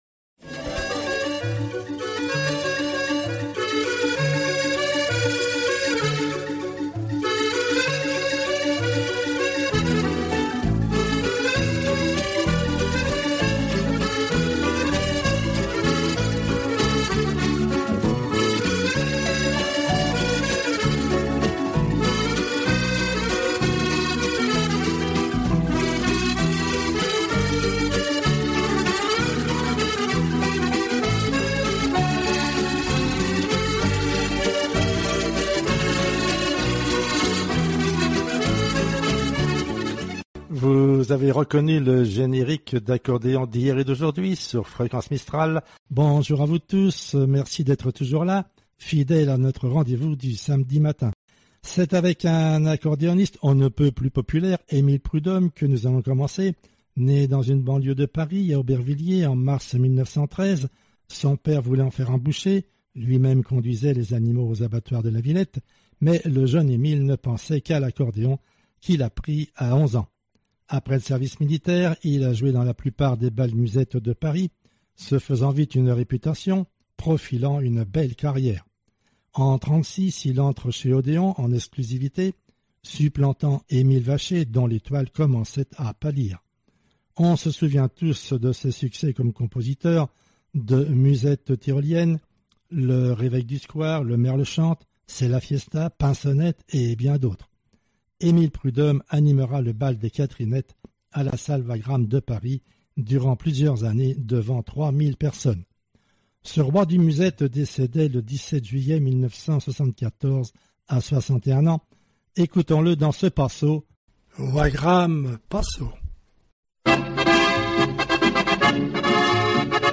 Musique d’Afrique du sud